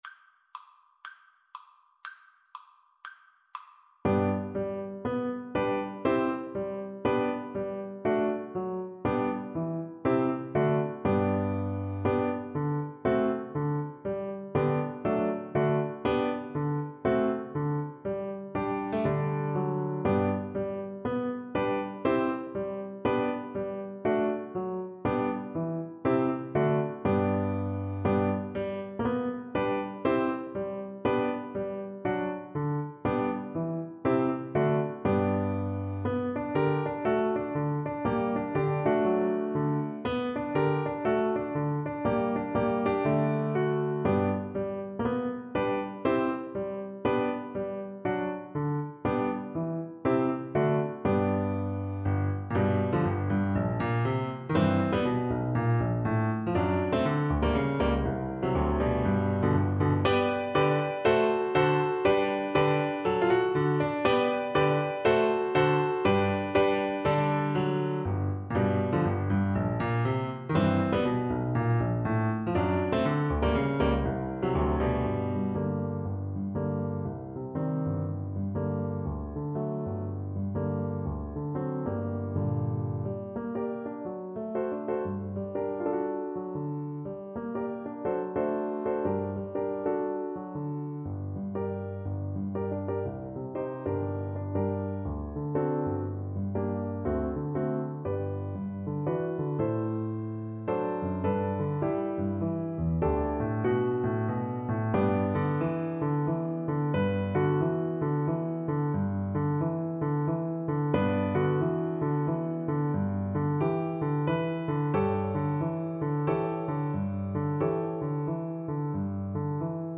CelloPiano
blues, funk, country and boogie-woogie.
2/4 (View more 2/4 Music)
THEME: Allegro =c.120 (View more music marked Allegro)
Cello  (View more Intermediate Cello Music)
Jazz (View more Jazz Cello Music)
Rock and pop (View more Rock and pop Cello Music)